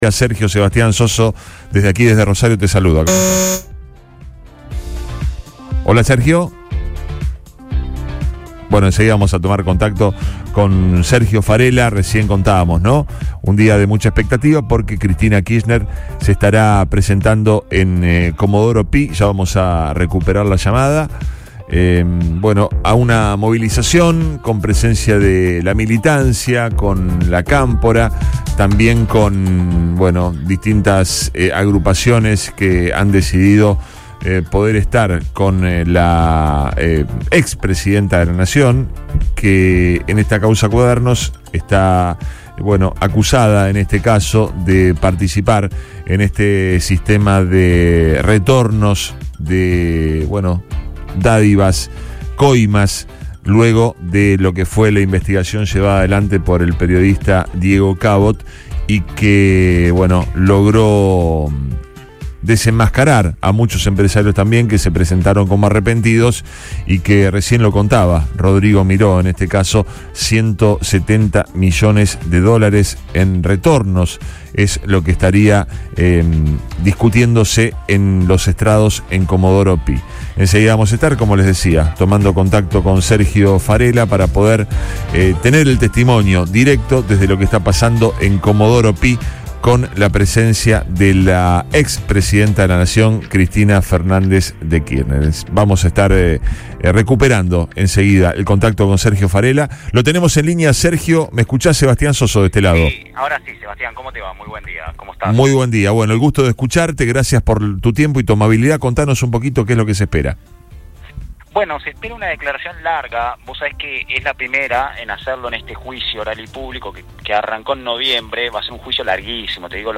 En diálogo con el programa Río Extra Primera Hora por FM Río 96.9